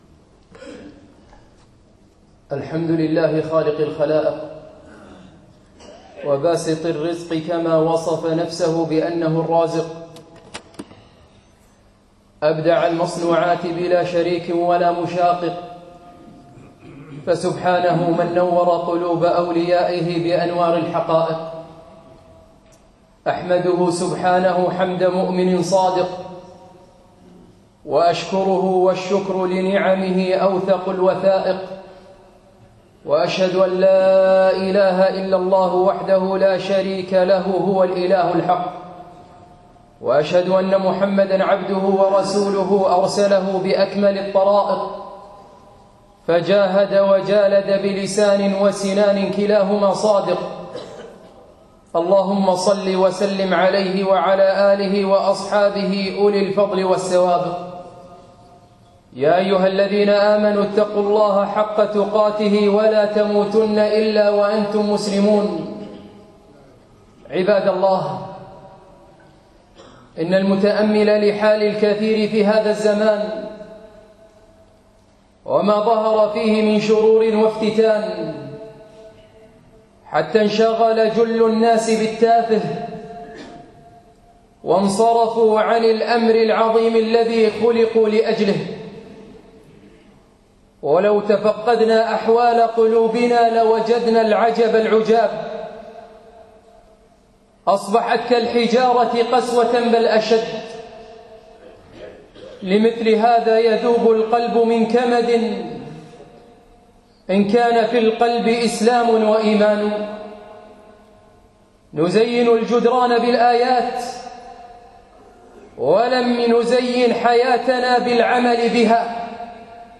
الجمعة 8 1 2016 مسجد العلاء بن عقبة ضاحية الفردوس